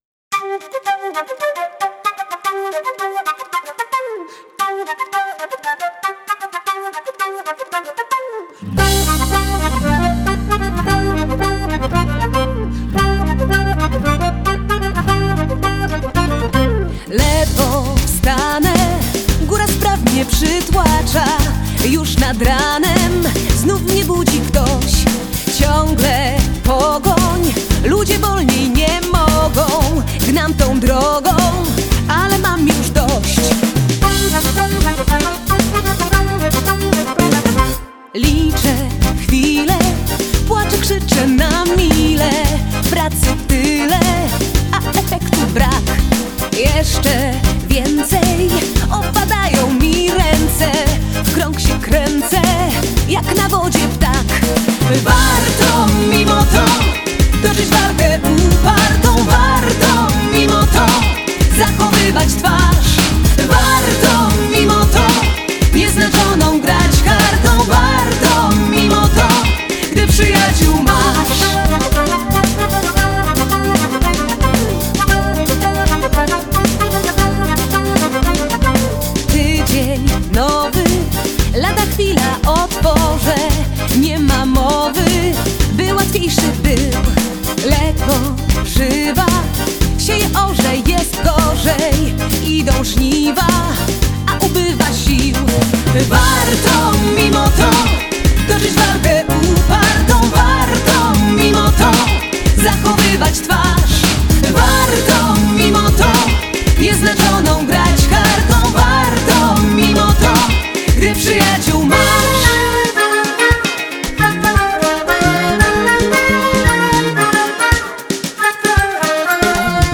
Genre: Folk-Rock